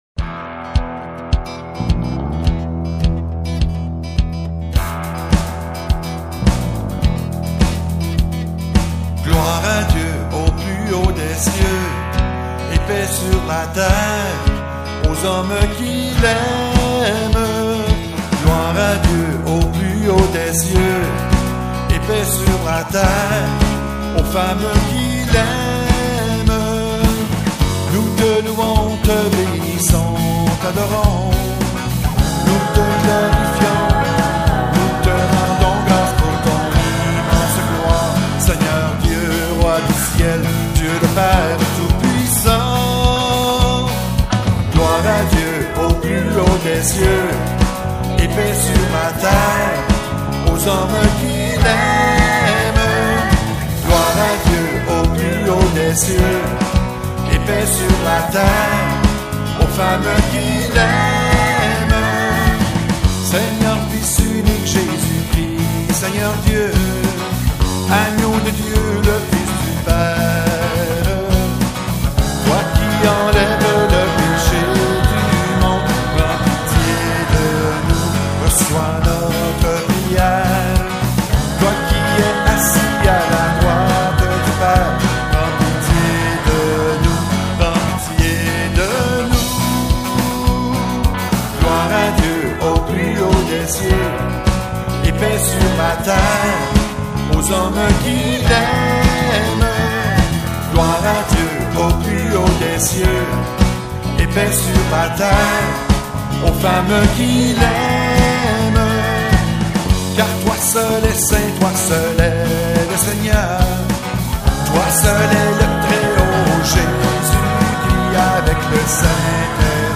Chants divers
gloire_a_dieu_chant.mp3